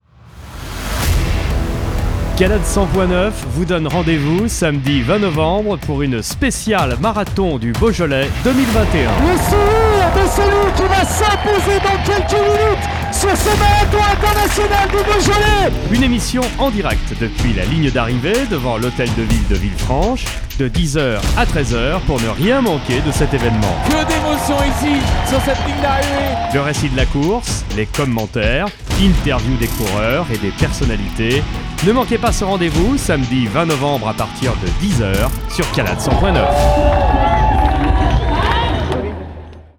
EMISSION SPECIALE !